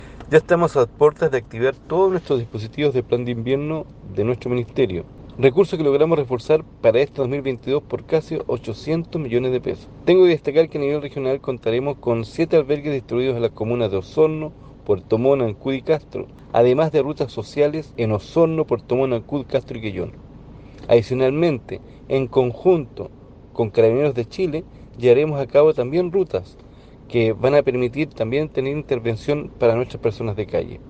El Seremi de Desarrollo Social y Familia, Enzo Jaramillo, explicó que el presupuesto para avanzar en esta iniciativa se reforzó en casi 800 millones de pesos.